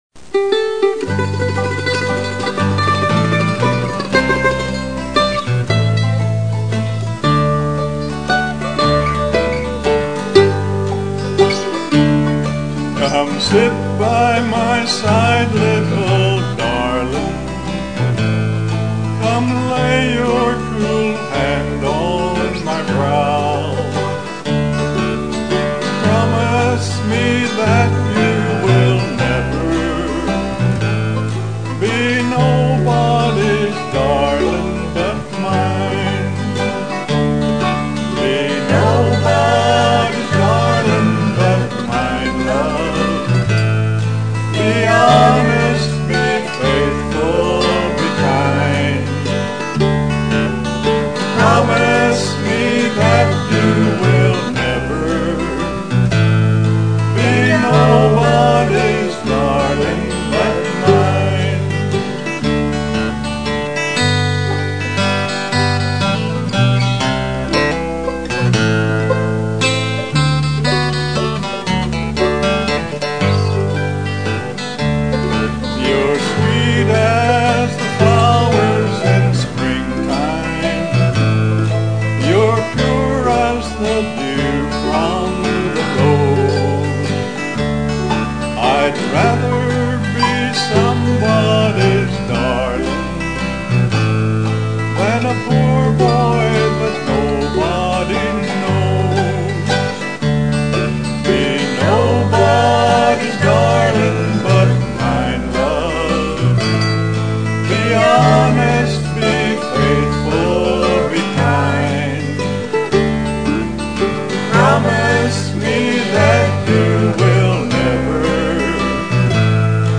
mandolin, vocals
bass, vocals
guitar, banjo, vocals